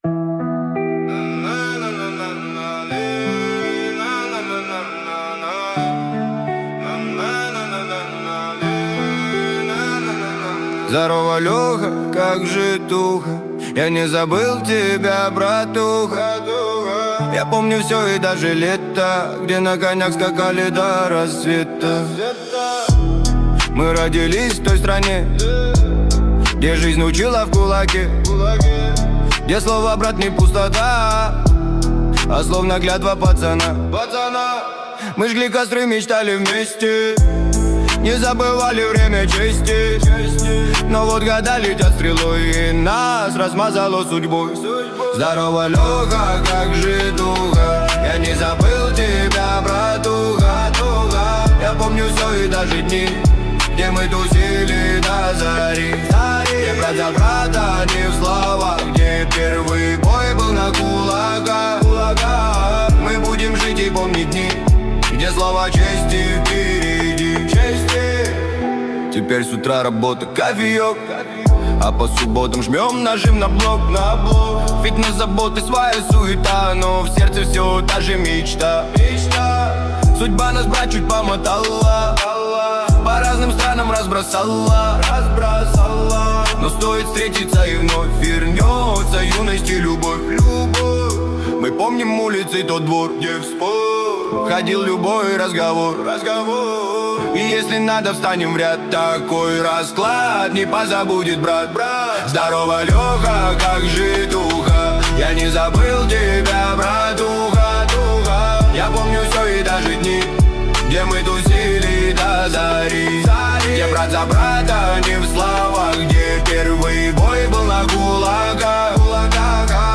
Песня ИИ